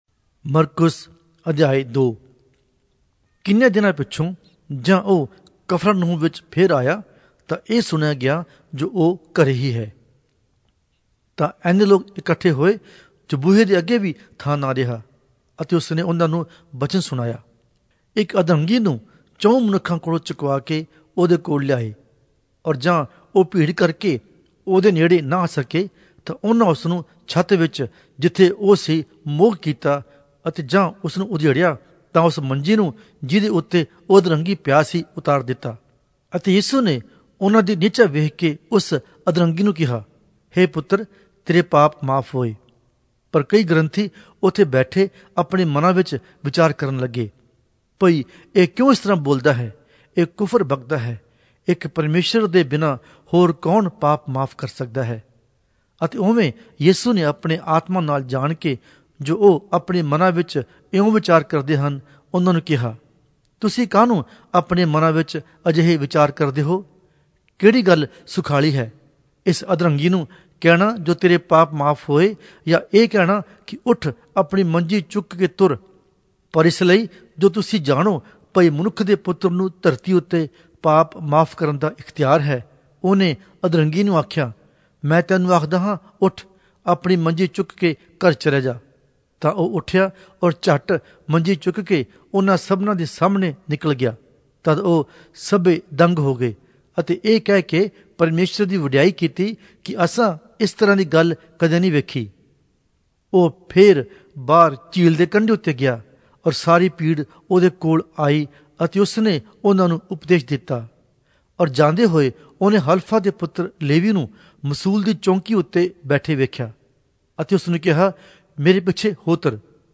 Punjabi Audio Bible - Mark 5 in Hcsb bible version